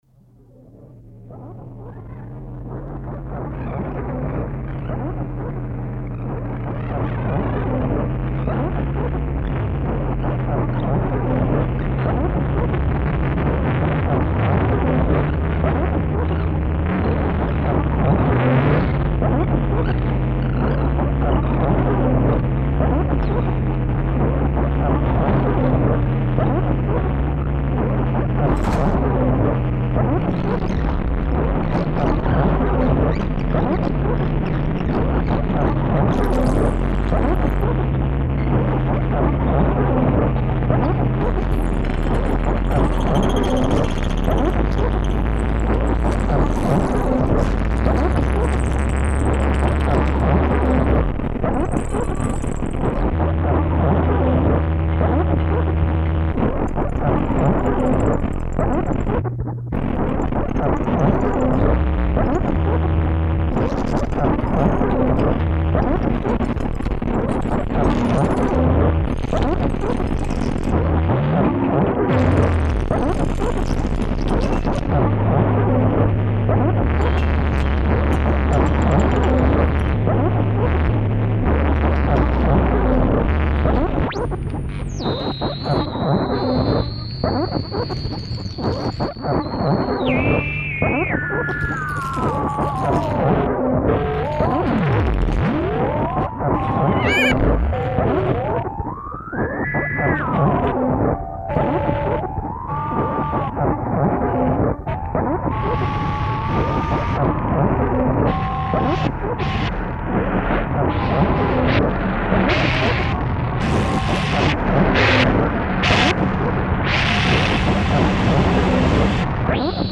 Two unreleased long tracks recorded in 1982.
Remastered from original master tapes.
This is Industrial Music.